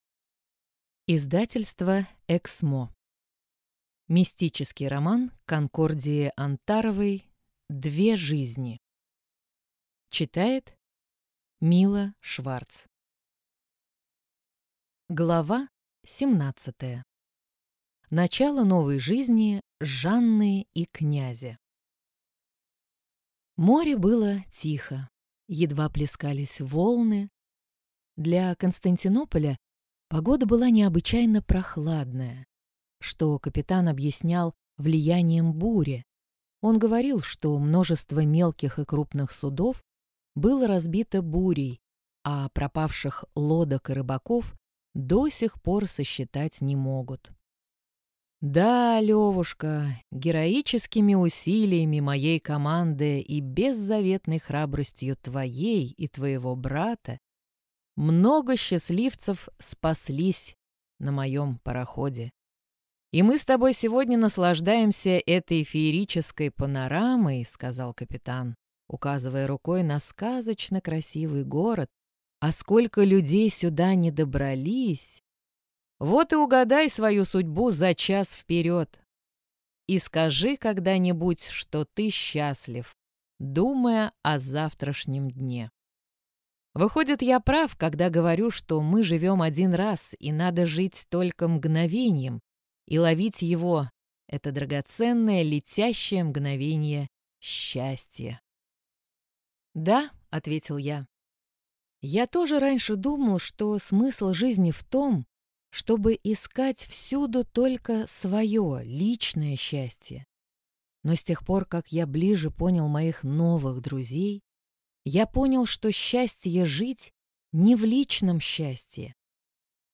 Аудиокнига Две жизни. Часть 1. Книга 2 | Библиотека аудиокниг